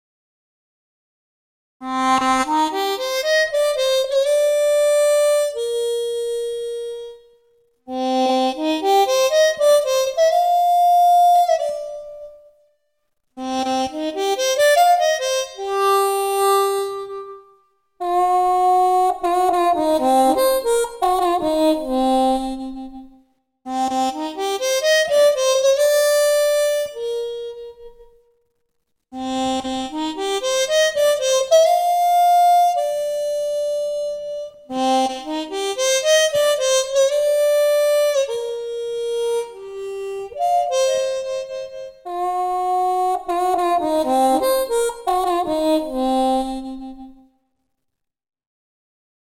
harmonica - blues - calme - melancolie - paysage